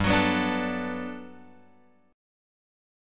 G Major 9th